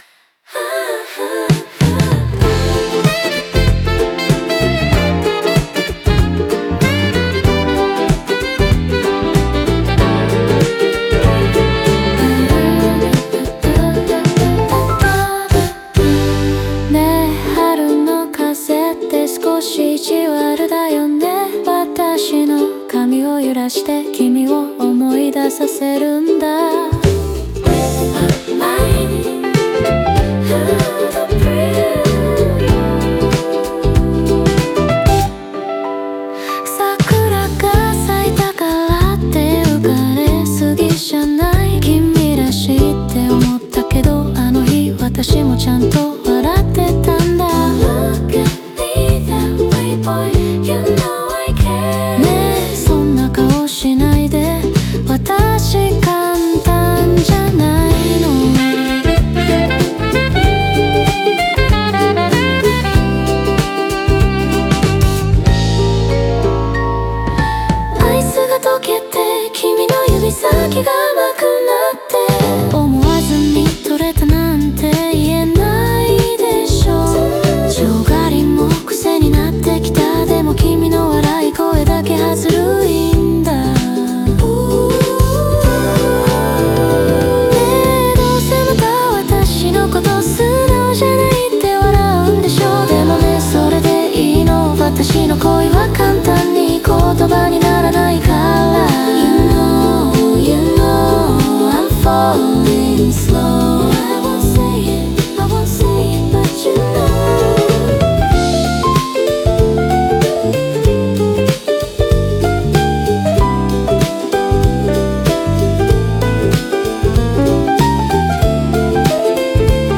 オリジナル曲♪
英語のバックコーラスは、彼女の「本音」や「心の声」として機能し、語りの間に優しく重なり合う。